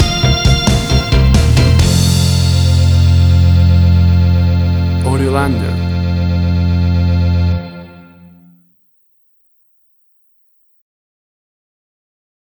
WAV Sample Rate: 16-Bit stereo, 44.1 kHz
Tempo (BPM): 134